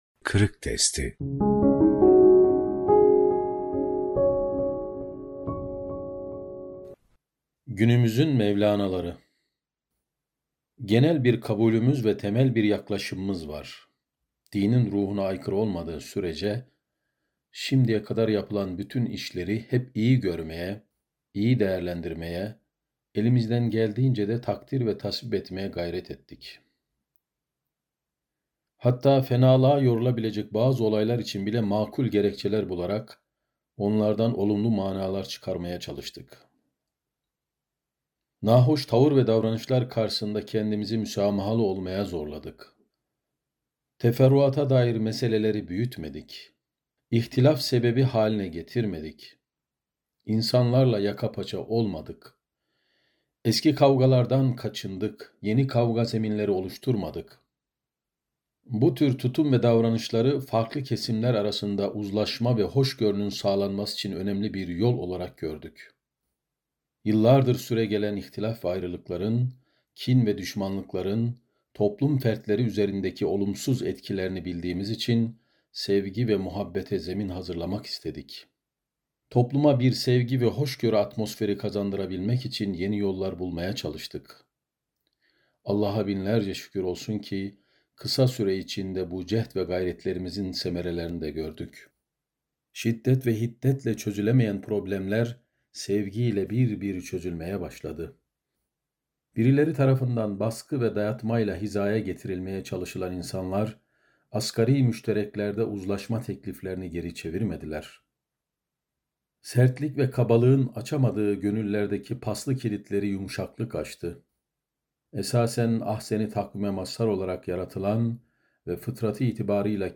Günümüzün Mevlanaları - Fethullah Gülen Hocaefendi'nin Sohbetleri